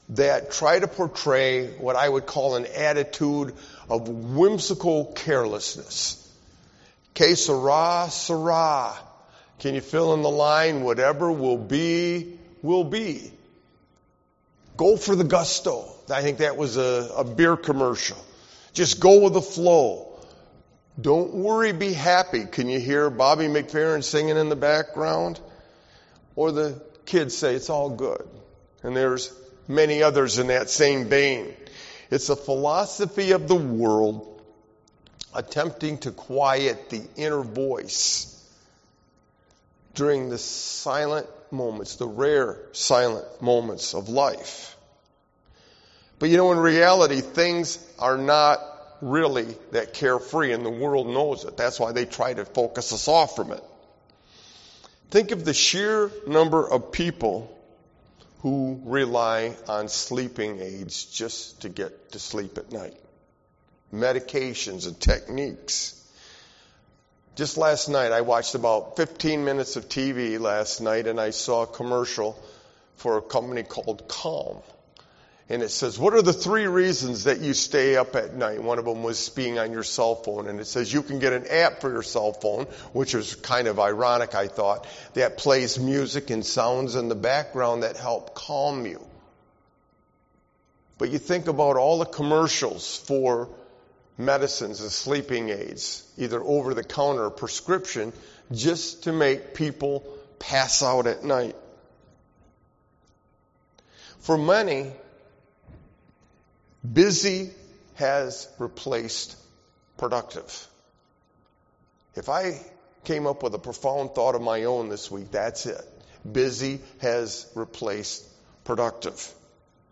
Sermon-Why-a-Sovereign-God-is-crucial-51522.mp3